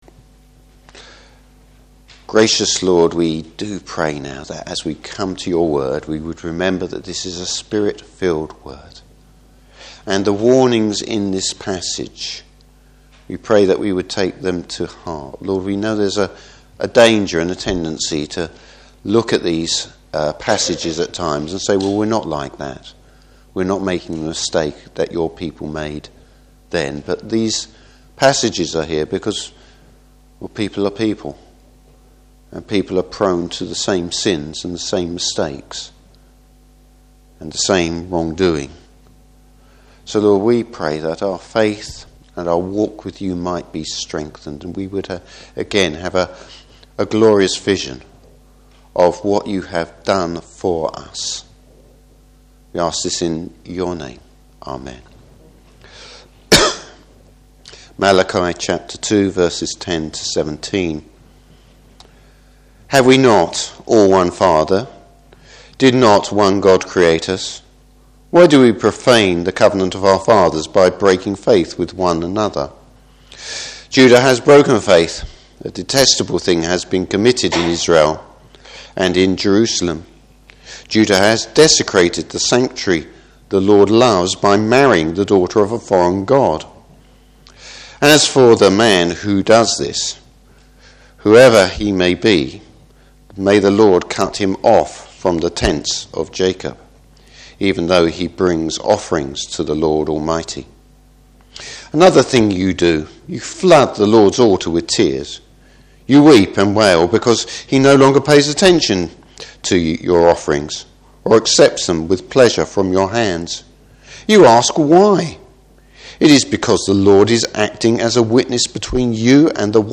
Service Type: Morning Service Israel’s unfaithfulness exposed!